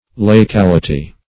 laicality - definition of laicality - synonyms, pronunciation, spelling from Free Dictionary
Search Result for " laicality" : The Collaborative International Dictionary of English v.0.48: Laicality \La"ic*al"i*ty\, n. The state or quality of being laic; the state or condition of a layman.